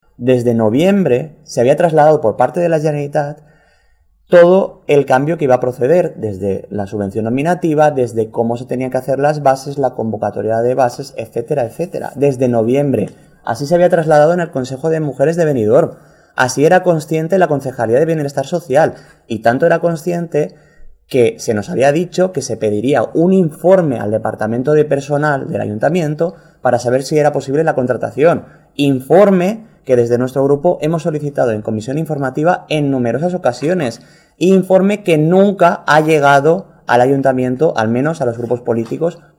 La regidora socialista, Cristina Escoda, juntament amb la portaveu del grup municipal Liberales, Gema Amor; i el portaveu del grup municipal Compromís-Los Verdes, Josep Bigorra, han comparegut en roda de premsa per exigir a l’equip de Govern del PP que redacte com més prompte possible les bases que han de regir la contractació d’un agent d’igualtat i no perdre així una subvenció de 25.000 euros atorgada per la Conselleria d’Igualtat i Polítiques Inclusives.